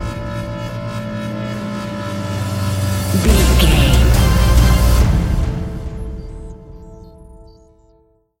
Epic / Action
Aeolian/Minor
Slow
strings
brass
drum machine
percussion
driving drum beat